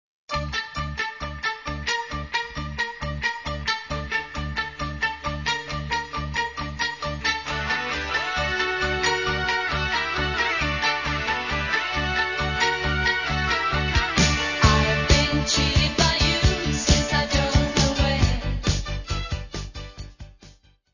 : stereo; 12 cm + folheto
Music Category/Genre:  Pop / Rock